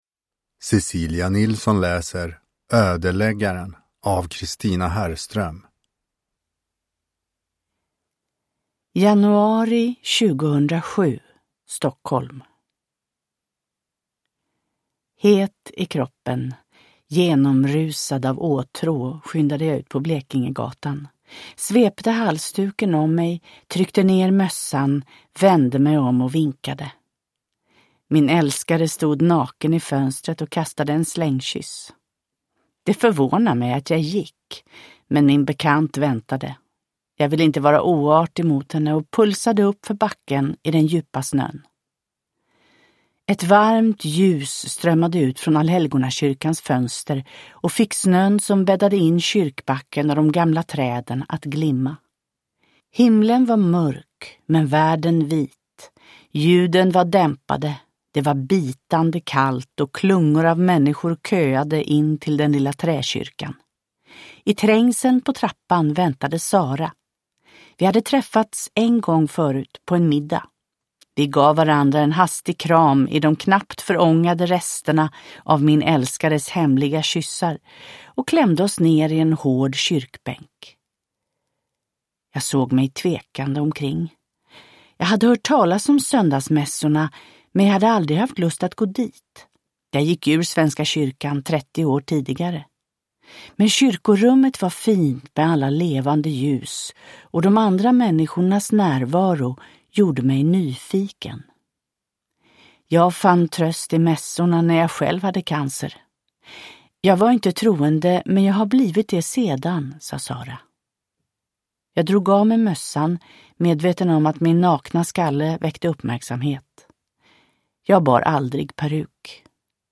Ödeläggaren – Ljudbok – Laddas ner